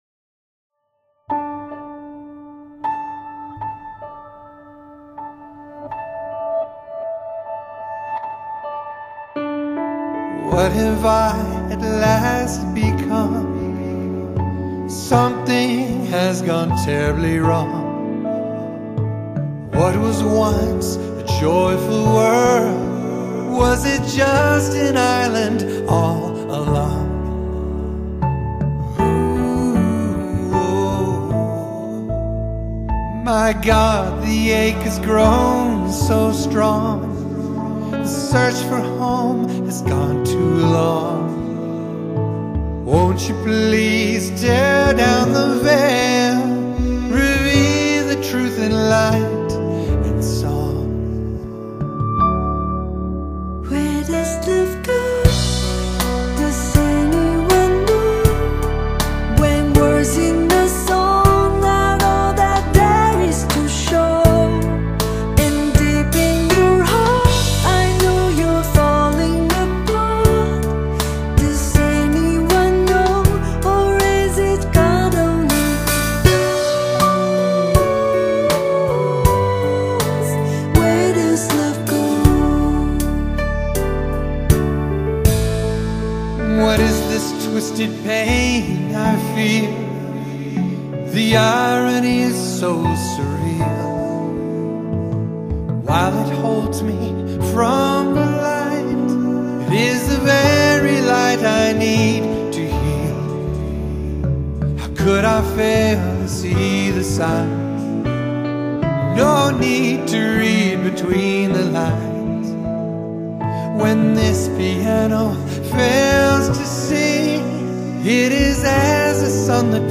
Genre: Pop, New Age